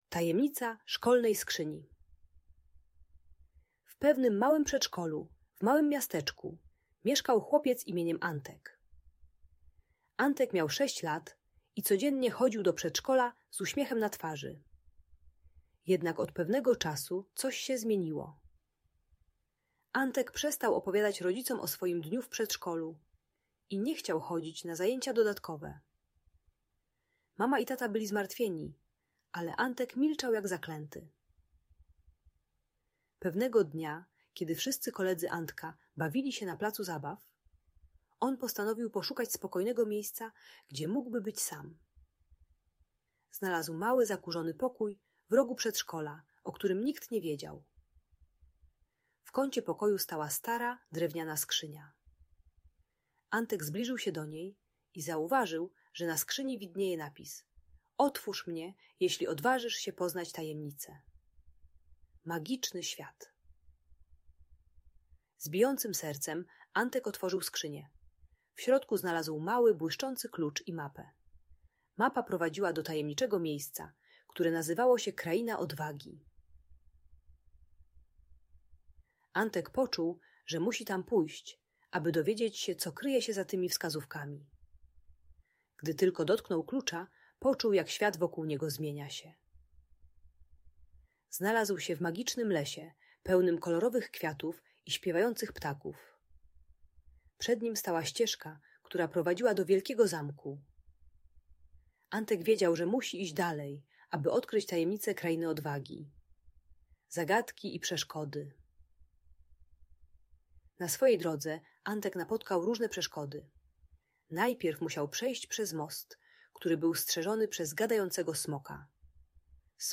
Tajemnica Szkolnej Skrzyni - magiczna OPOWIEŚĆ o odwadze - Audiobajka